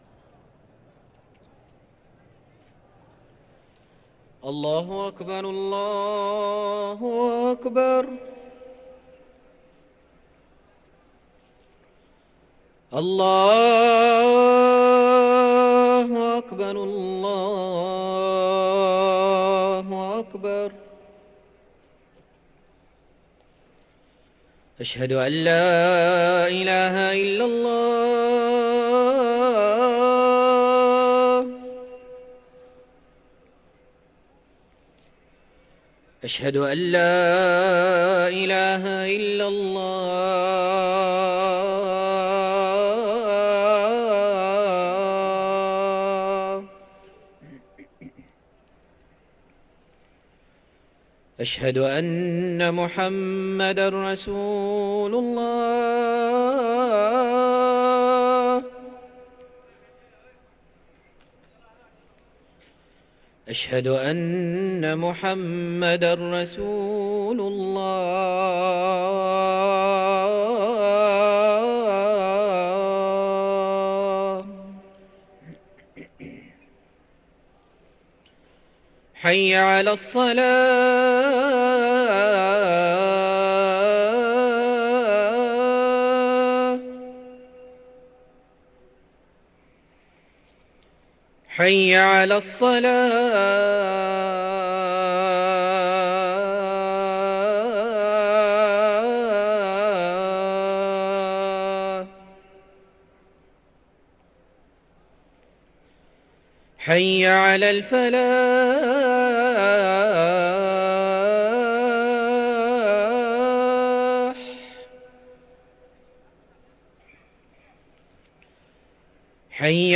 أذان الظهر
ركن الأذان